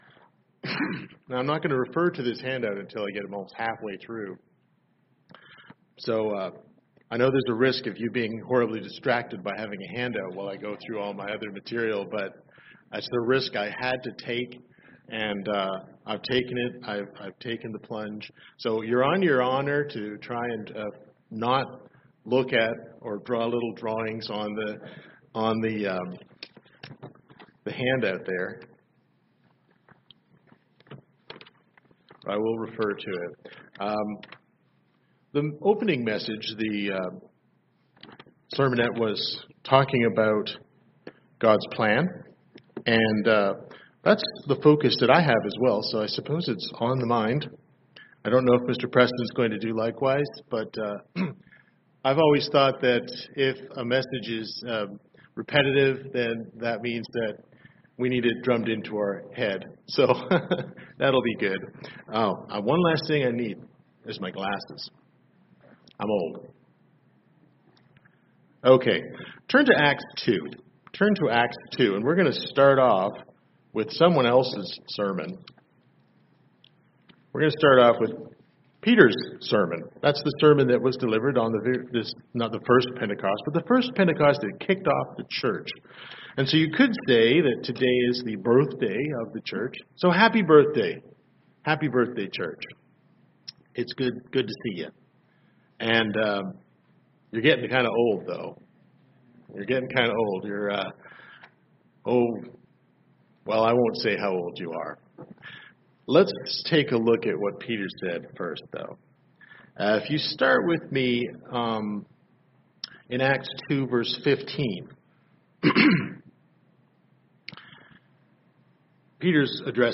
Sermons
Given in Charlotte, NC Hickory, NC